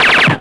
Suppressed Machine Gun
Basically a modified version of the sniper silencer.
preview with echo
silencermg_160.wav